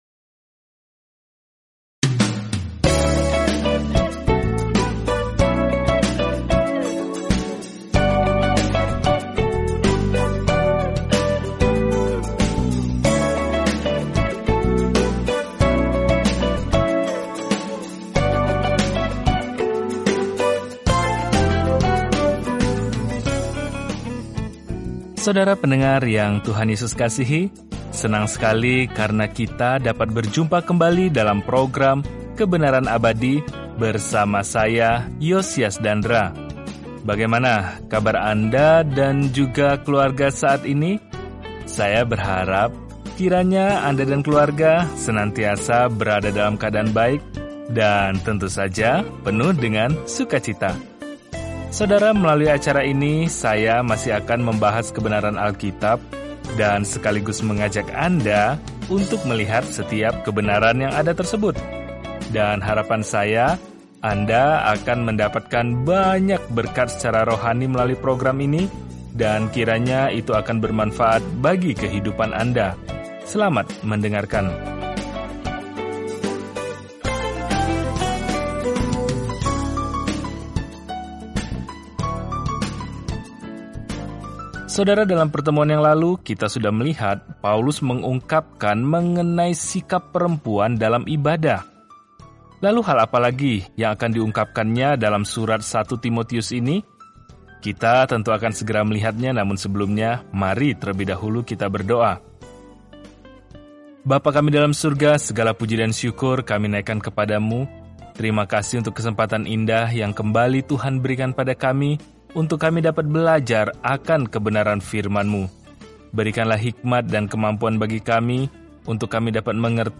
Firman Tuhan, Alkitab 1 Timotius 3:1-8 Hari 6 Mulai Rencana ini Hari 8 Tentang Rencana ini Surat pertama kepada Timotius memberikan indikasi praktis bahwa seseorang telah diubah oleh Injil – tanda-tanda kesalehan yang sejati. Telusuri 1 Timotius setiap hari sambil mendengarkan pelajaran audio dan membaca ayat-ayat tertentu dari firman Tuhan.